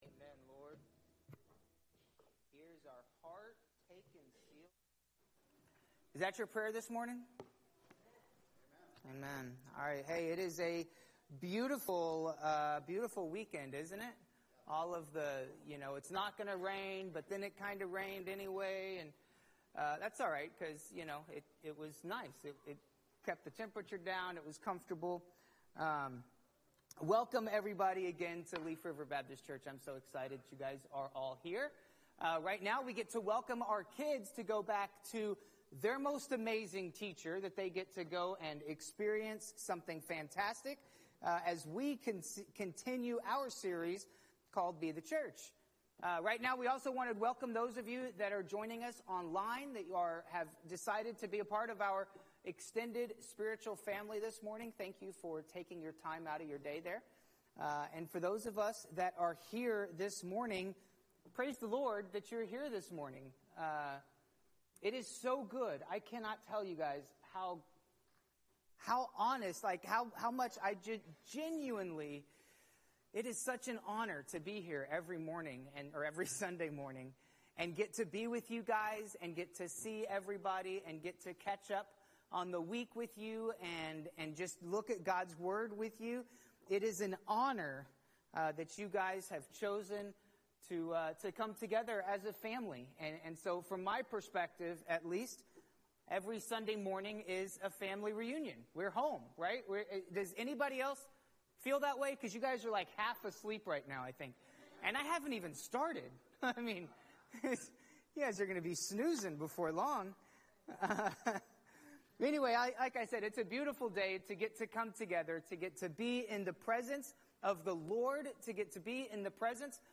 Messages | Leaf River Baptist Church